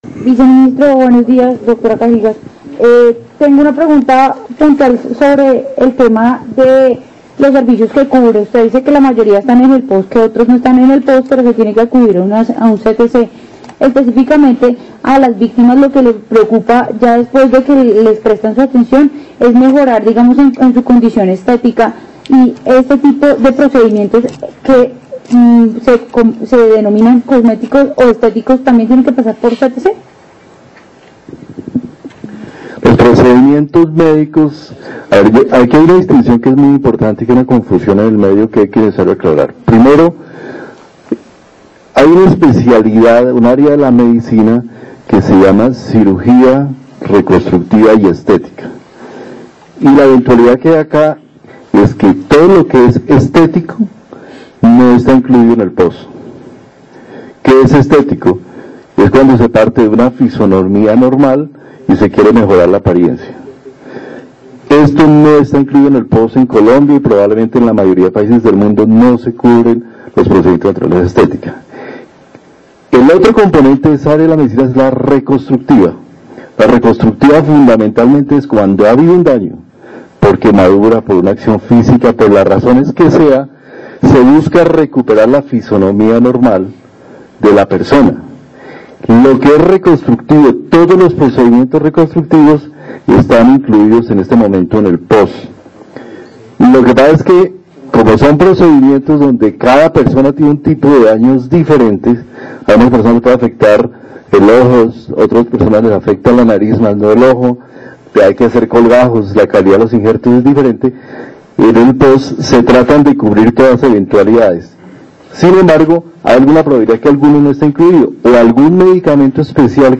Audio, El Viceministro, Fernando Ruíz Gómez responde preguntas sobre las medidas de prevención y atención de personas agredidas con ácido.